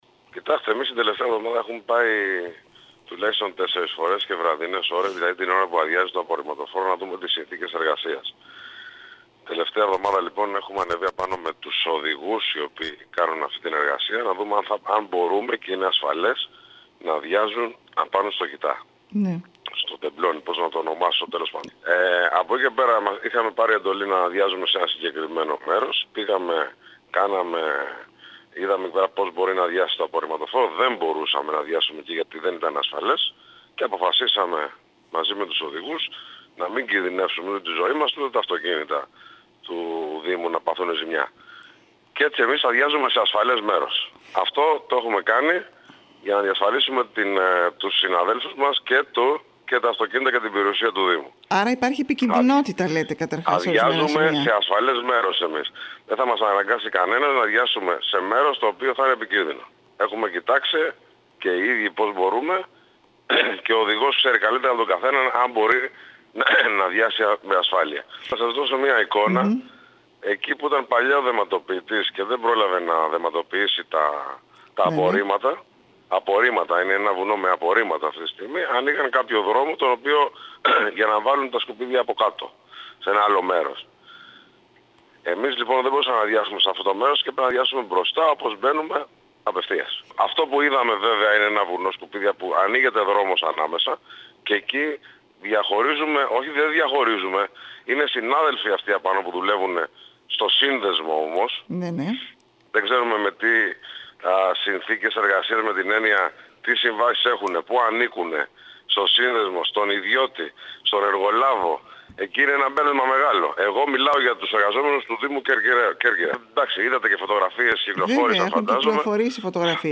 Όπως είπε μιλώντας στην ΕΡΤ Κέρκυρας